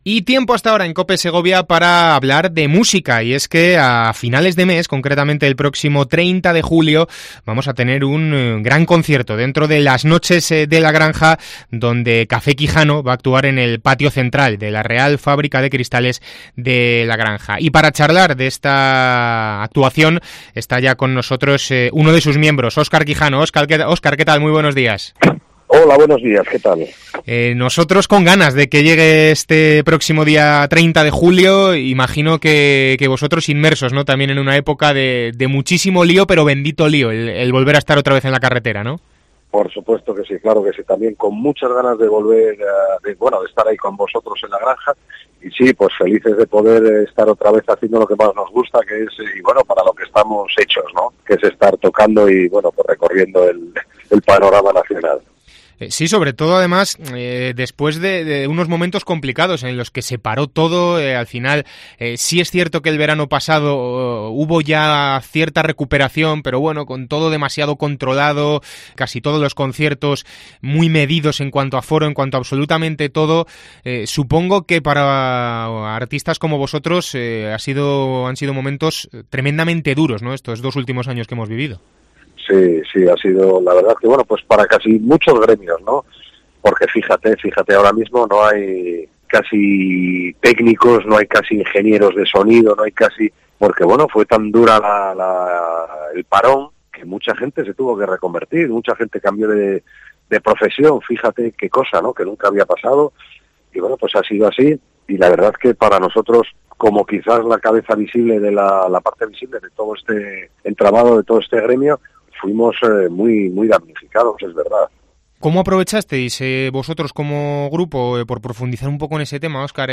Entrevista a Café Quijano